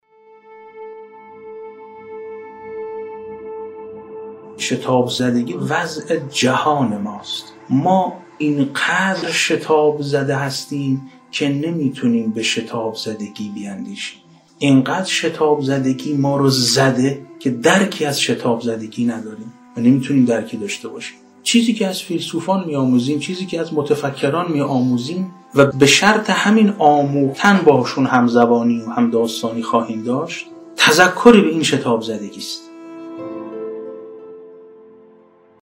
سخرانی